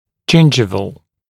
[‘ʤɪnʤɪvəl] [ʤɪn’ʤaɪvəl][‘джиндживэл] [джин’джайвэл]десневой, имеющий отношение к десне